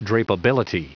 Prononciation du mot drapeability en anglais (fichier audio)
Prononciation du mot : drapeability